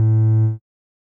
Techmino/media/sample/bass/13.ogg at beff0c9d991e89c7ce3d02b5f99a879a052d4d3e